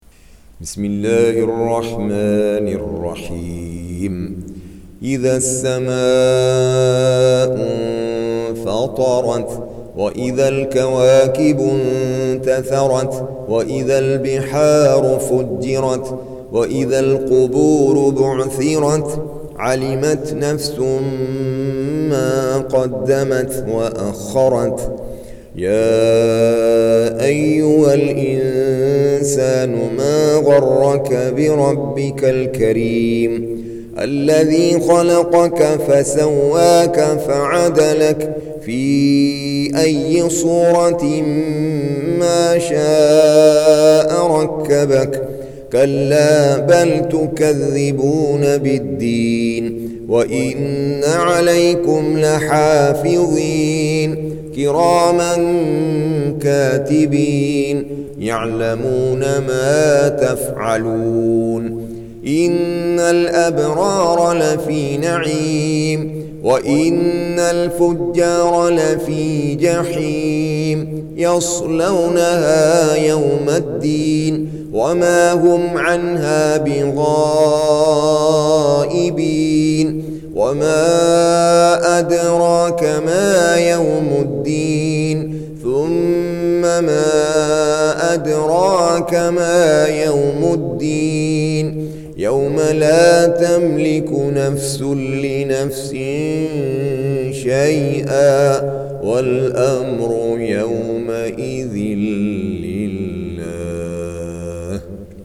82. Surah Al-Infit�r سورة الإنفطار Audio Quran Tarteel Recitation
Surah Repeating تكرار السورة Download Surah حمّل السورة Reciting Murattalah Audio for 82. Surah Al-Infit�r سورة الإنفطار N.B *Surah Includes Al-Basmalah Reciters Sequents تتابع التلاوات Reciters Repeats تكرار التلاوات